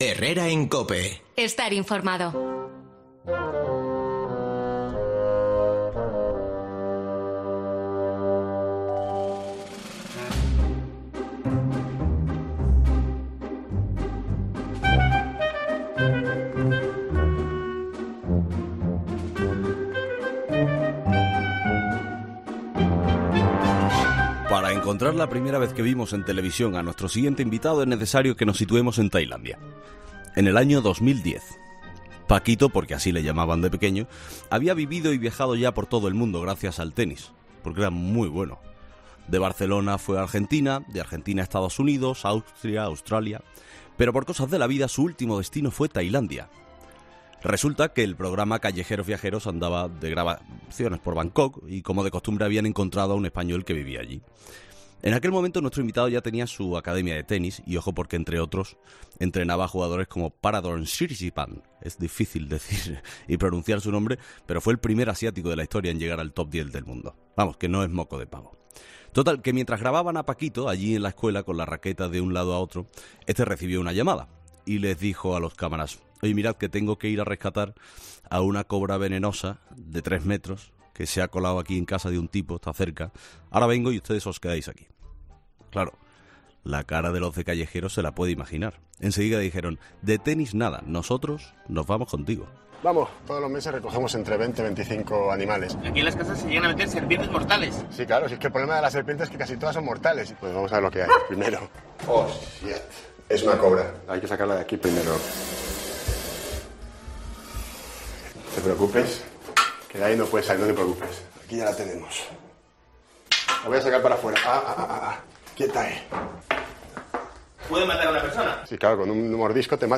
'Paquito' como lo conocían de Pequeño, ha pasado por 'Herrera en COPE' para hablar de su nuevo proyecto, un santuario de animales en España